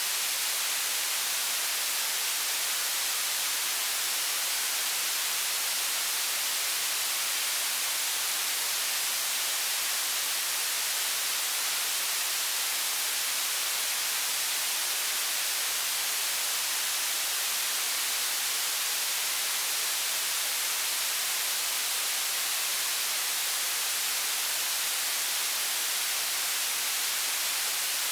rain_level_3.ogg